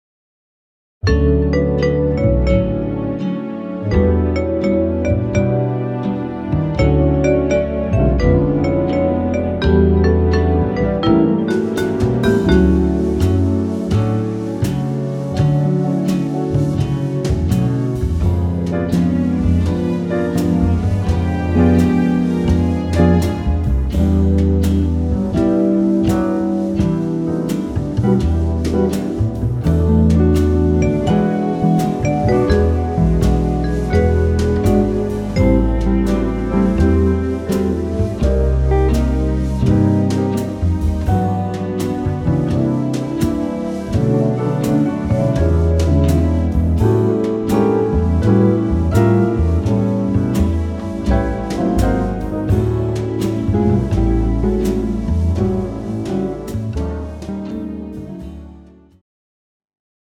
jazz ballad style
tempo 84 bpm
female backing track
This track is in slow tempo jazz ballad style.